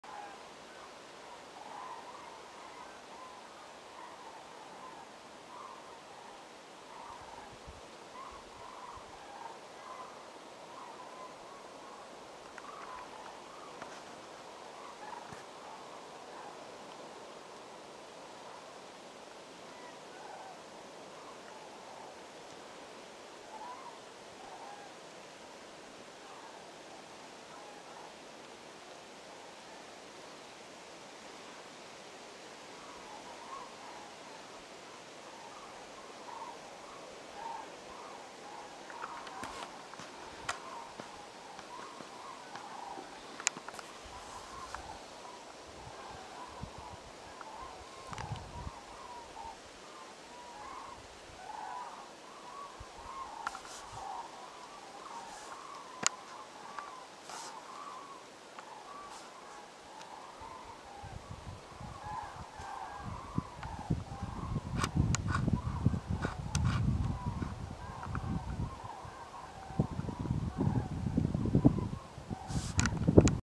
Вы можете слушать онлайн или скачать знаменитое курлыканье, крики и звуки общения этих грациозных птиц в формате mp3.
Звуки улетающих журавлей в небе, клин летит на юг